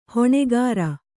♪ hoṇegāra